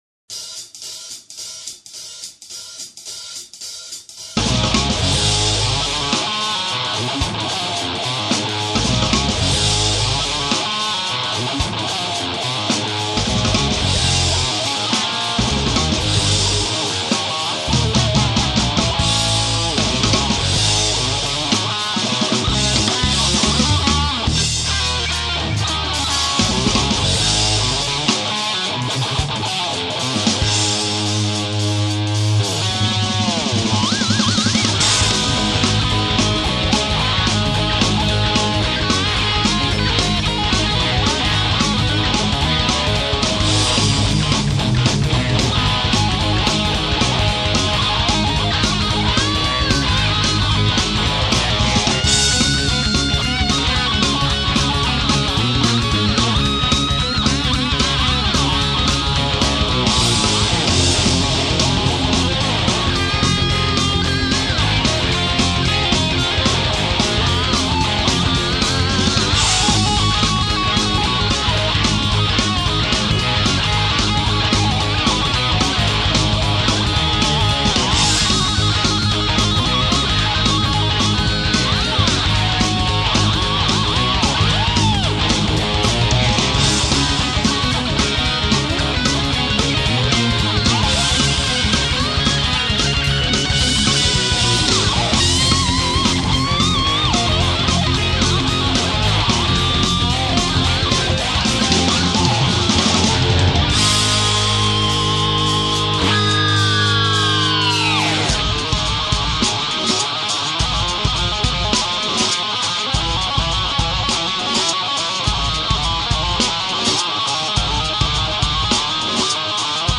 Darum benutze ich Hintergrundmusik, wenn ich spiele.